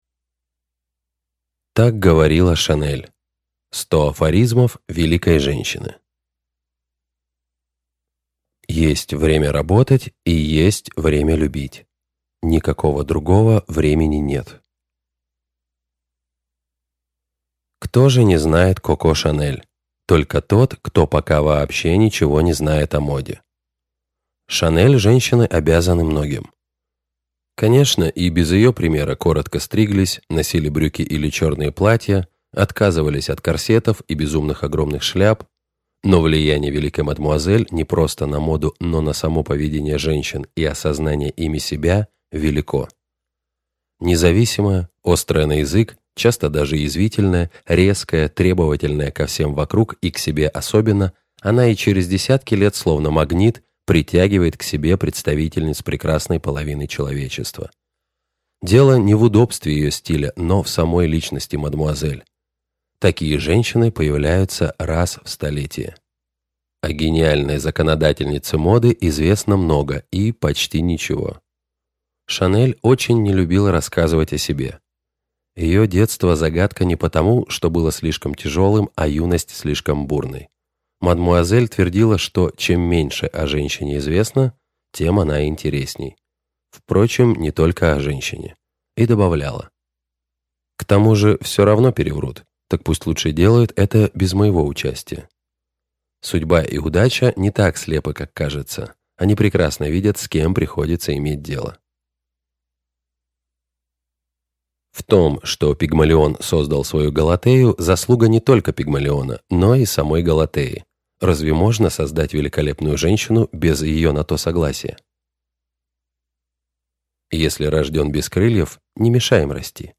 Аудиокнига Так говорила Шанель. 100 афоризмов великой женщины | Библиотека аудиокниг